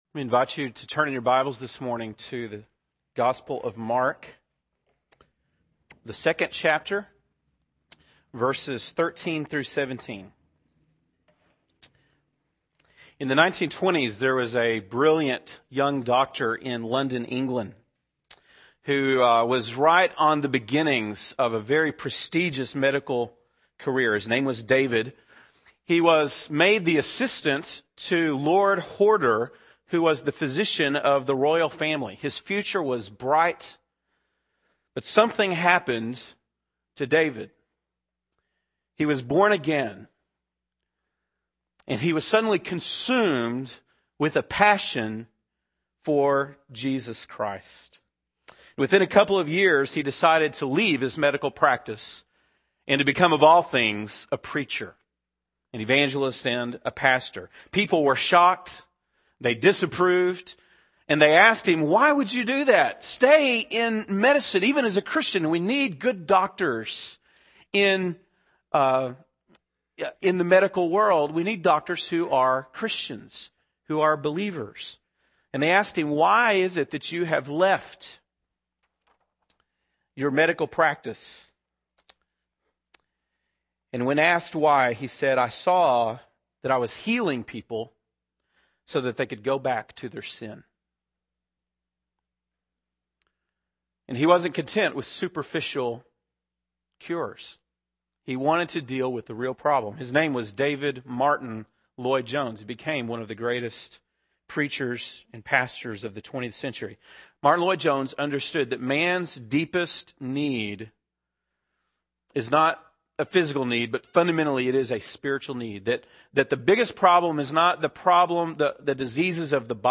April 22, 2007 (Sunday Morning)